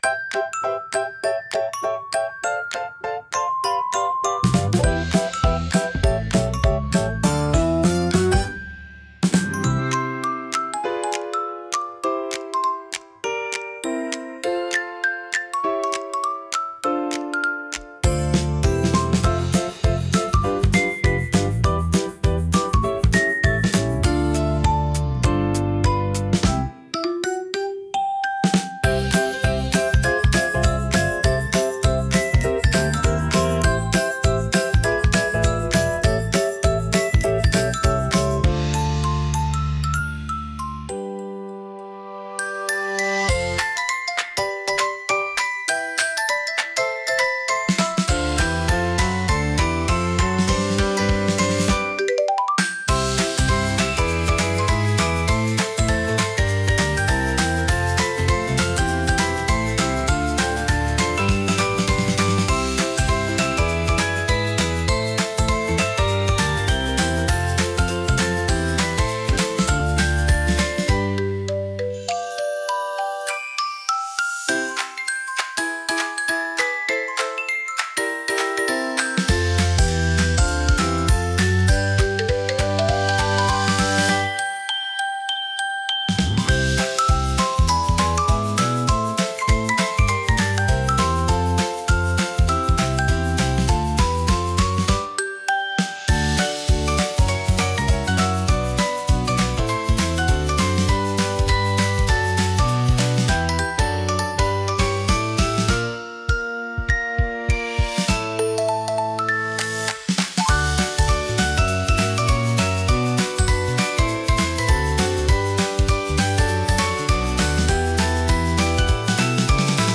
わくわくピコピコBGM